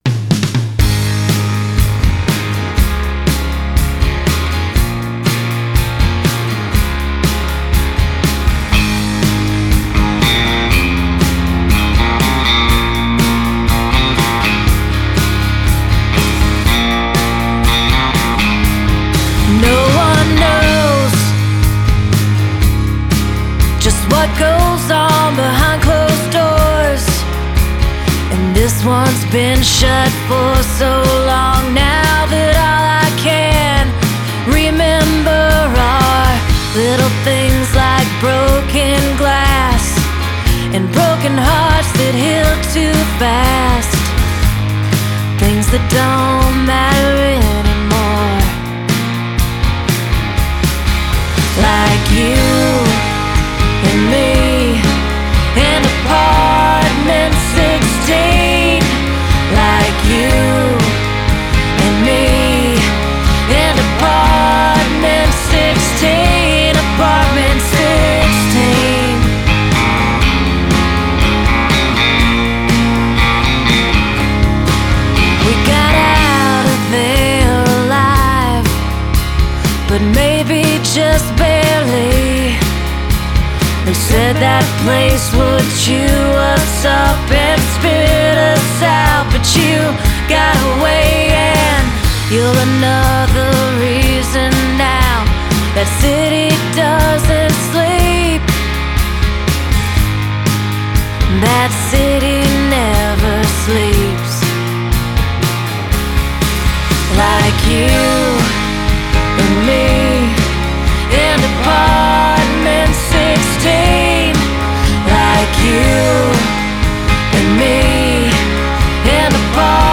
California rock, with a little country flavoring.